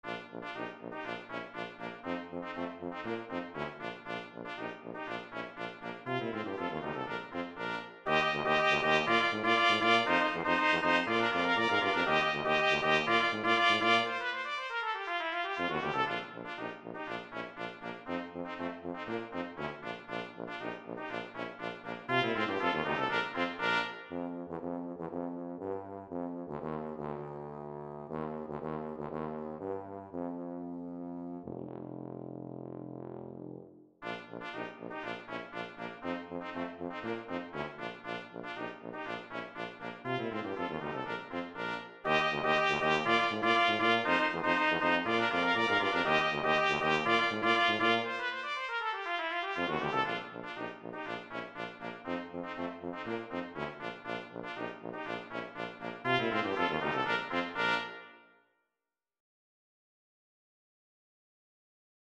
This is a short little piece. It is 60 seconds long and only uses three instruments (two trumpets and a tuba). I composed it using the Finale software.